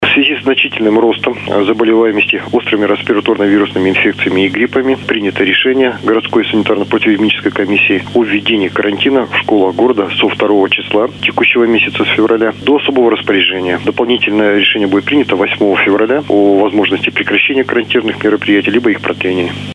«запись с телефона»